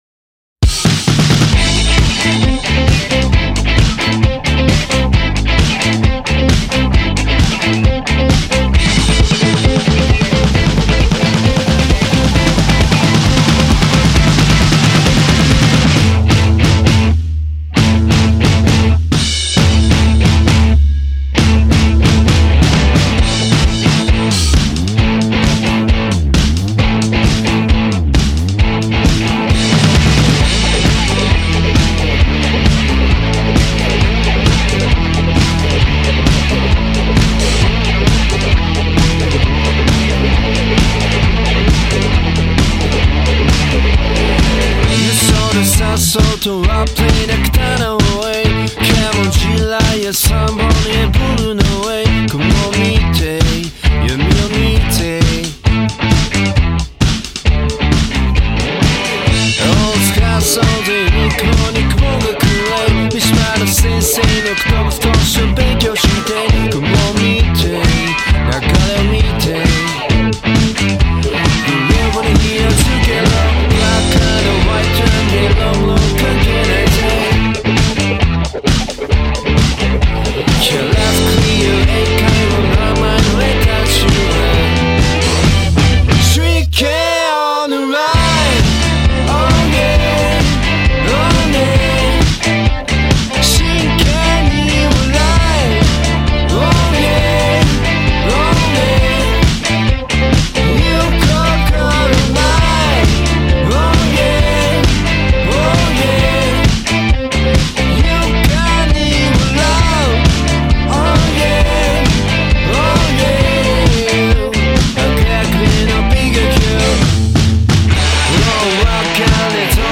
前者在空洞中透露出紧实干练的力量，后者则以喧嚣掩饰空虚和匮乏。